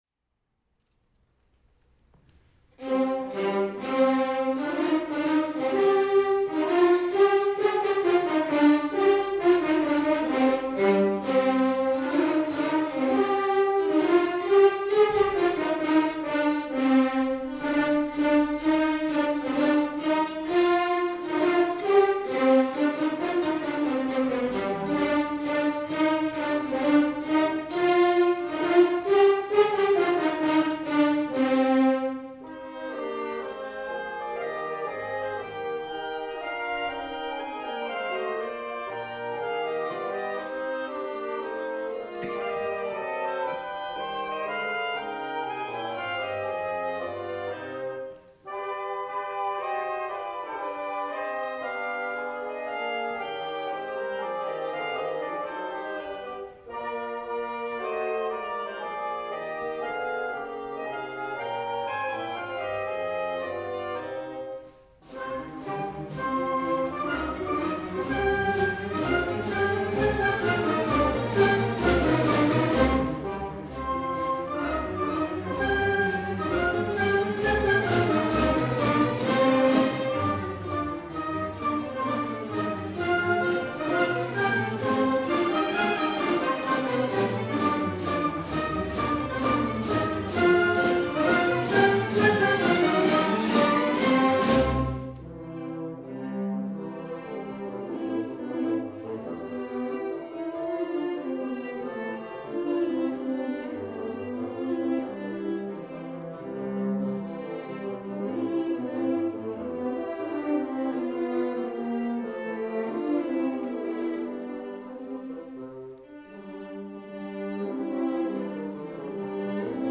Concert recordings